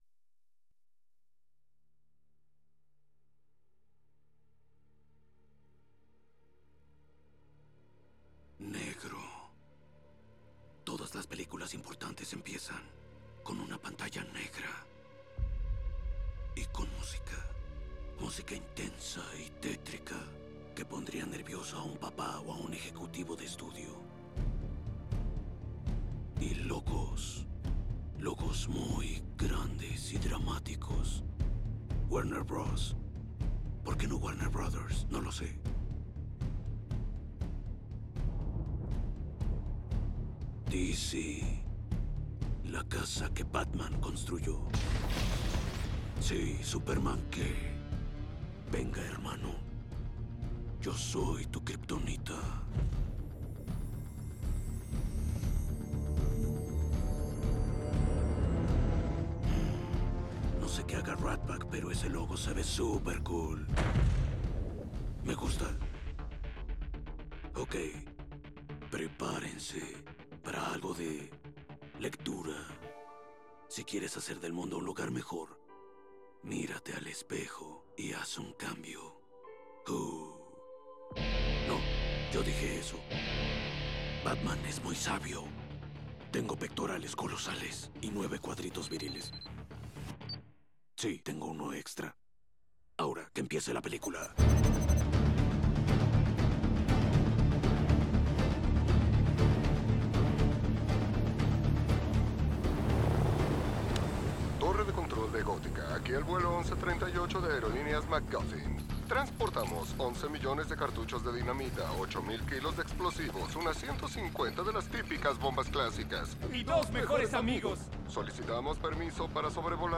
Movie Dialogues